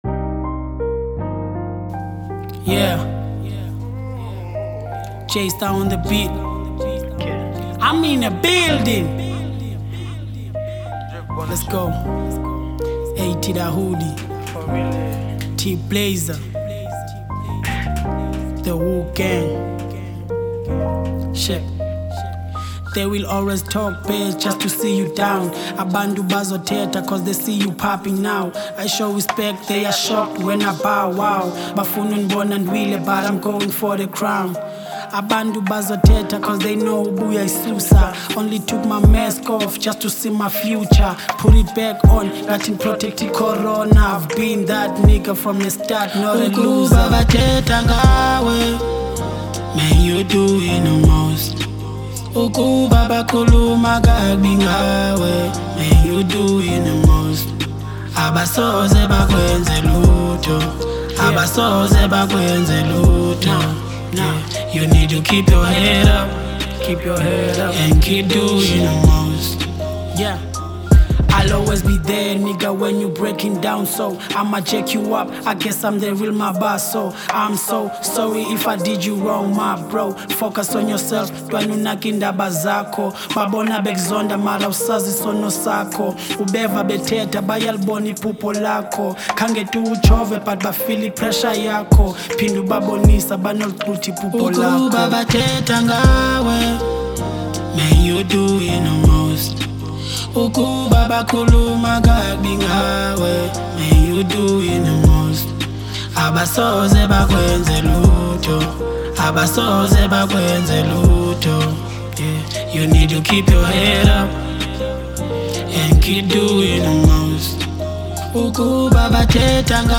02:36 Genre : Afro Pop Size